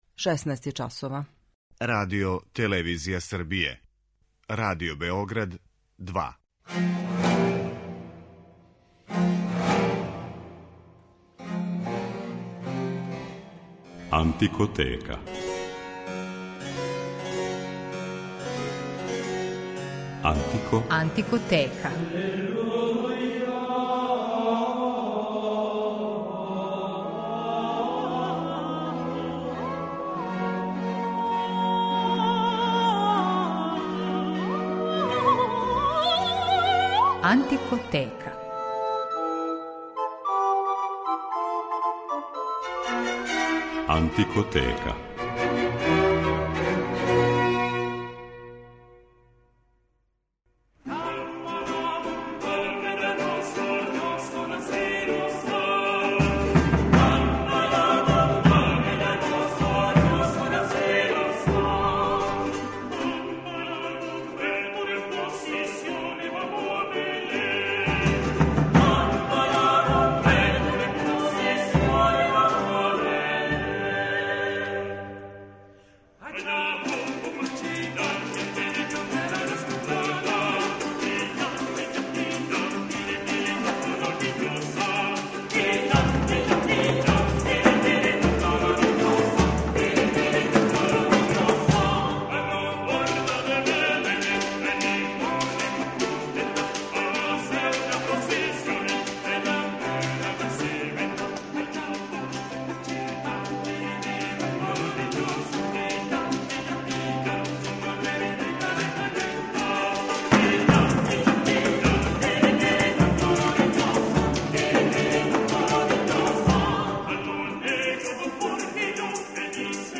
Она се стапала са музиком староседелаца (Индијанаца, али и досељеника из Африке, робова), те је захваљујући необичним ритмовима и инструментаријуму добила специфичан звук и карактер.
Данашња емисија посвећена је латиноамеричкој музици 17. века коју ћете слушати у извођењу кубанског ансамбал „Арс лонга". У рубрици „Антикоскоп" говорићемо о једном старом, скоро заборављеном инструменту, triple pipe, својеврсној трострукој свирали која се по начину свирања категоризује као усне оргуље, а по грађи писка као троструки кларинет.